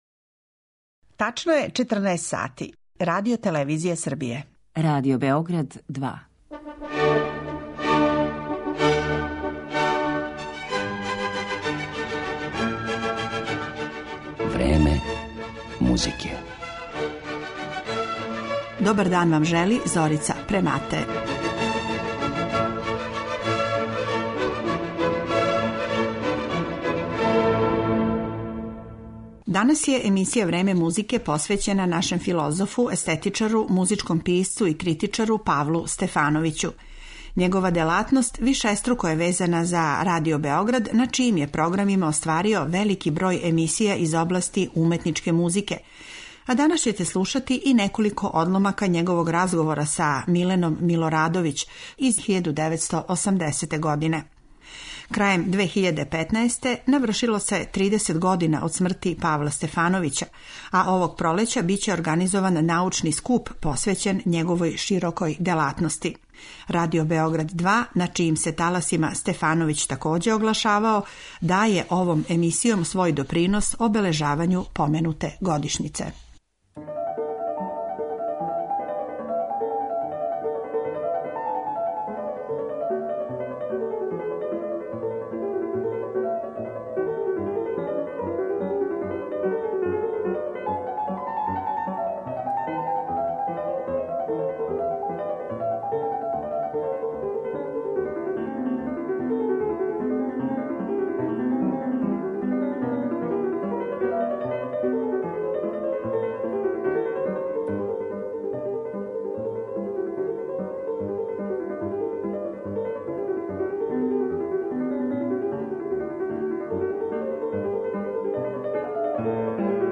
Захваљујући Тонском архиву Радио Београда, имаћете прилику и да чујете овог ерудиту, чије су многобројне емисије посвећене разним темама из области класичне музике обележиле програме нашег радија током неколико деценија прошлог века.